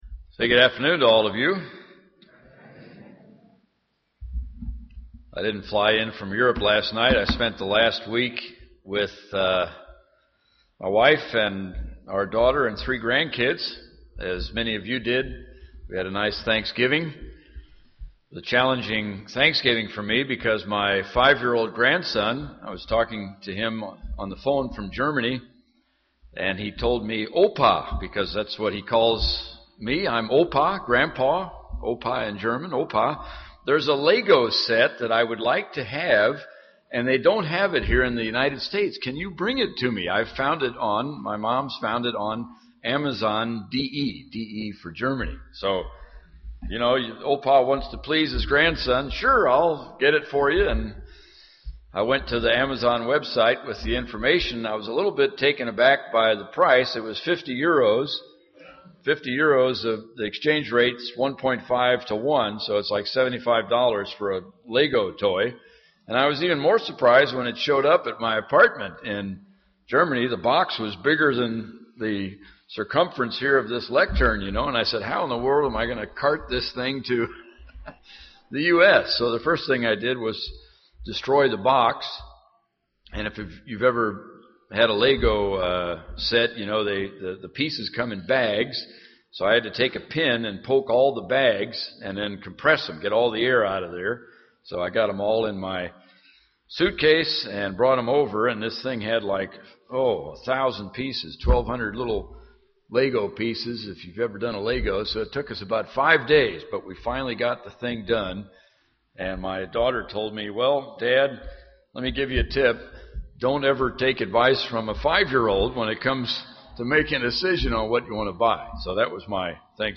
Given in Indianapolis, IN
UCG Sermon Studying the bible?